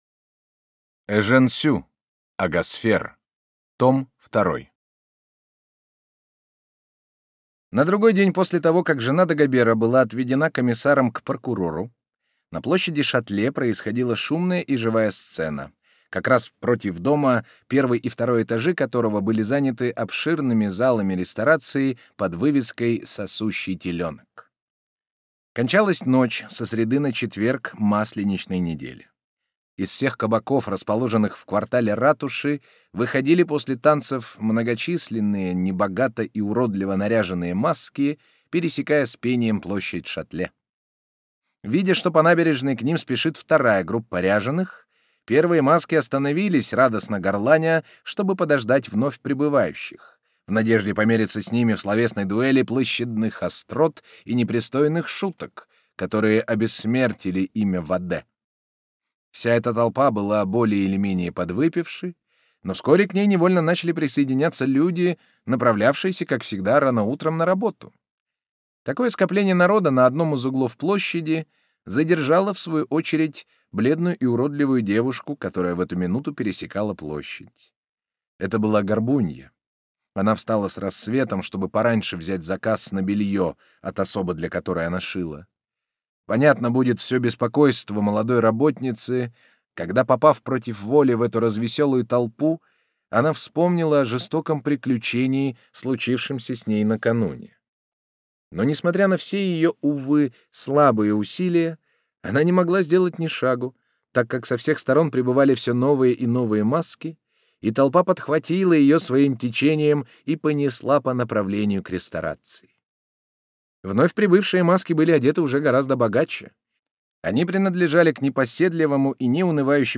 Аудиокнига Агасфер из жанра Проза - Скачать книгу, слушать онлайн